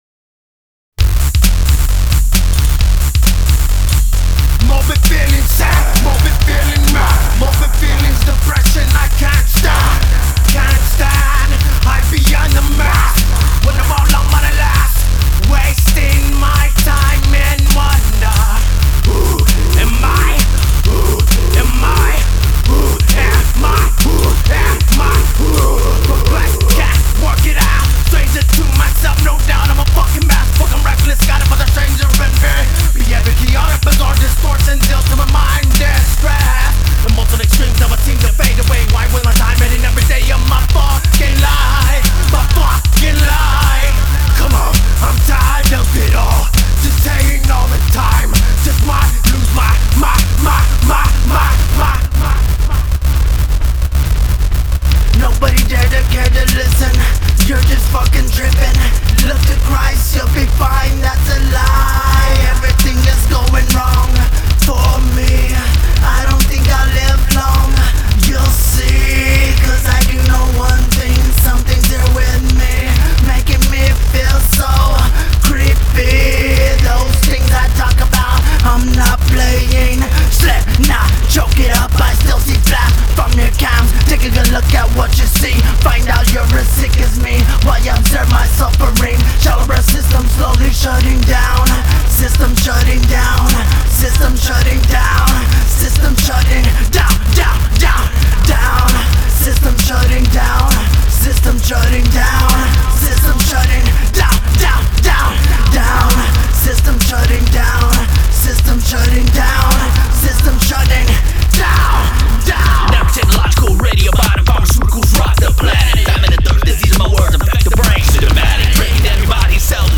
dance/electronic
Hip-hop
Industrial
Nu-metal